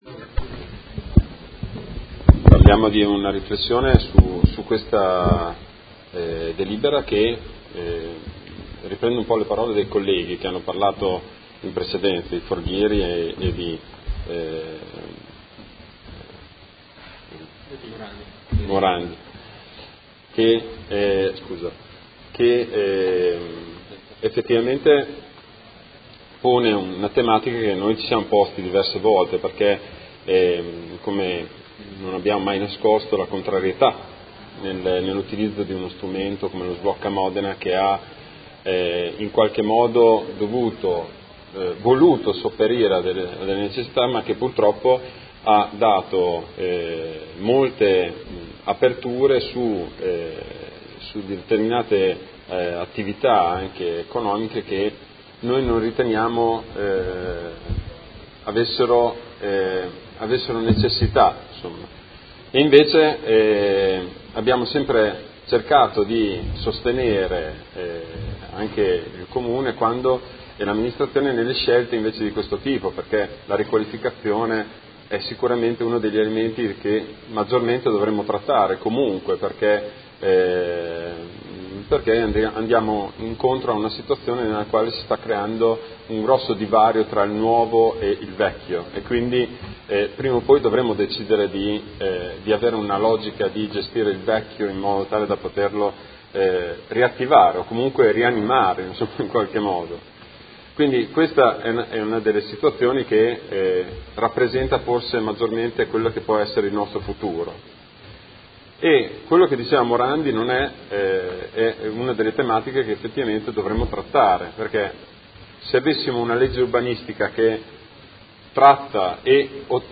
Marco Bortolotti — Sito Audio Consiglio Comunale